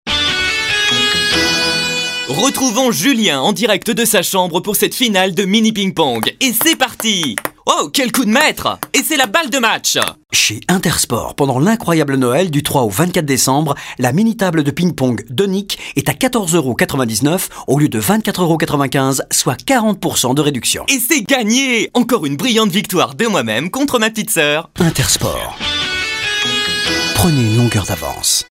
VOIX OFF JEUNE
Un timbre plus léger, une énergie rajeunie, un phrasé plus direct — tout est ajusté avec précision selon les besoins du projet.
4. INTERSPORT ado espiègle